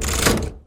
lever2.wav